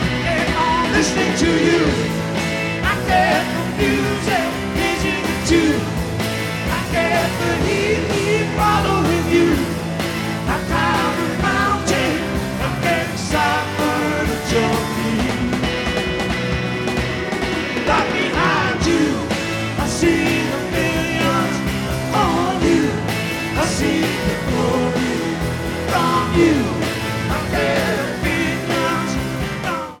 Portland (10-21-82) Radio Commercial(s) Master Tape